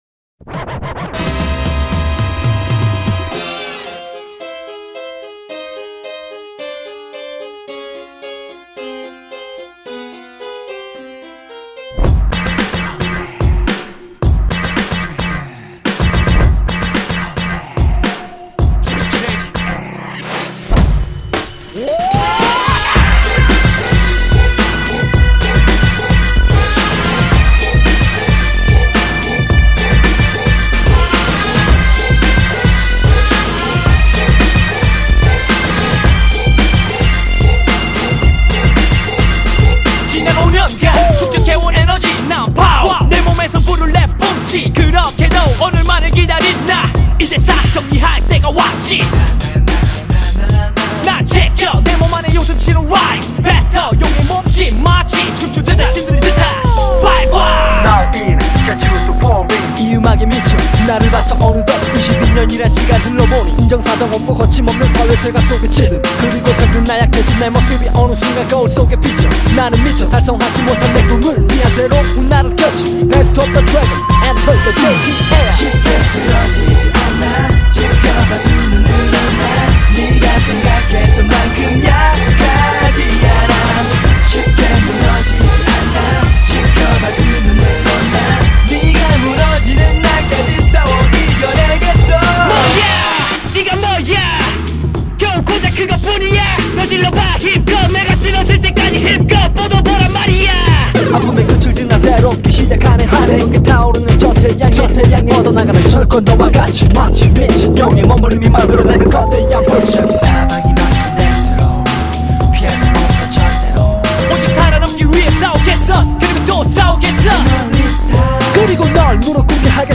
BPM110--1
Audio QualityPerfect (High Quality)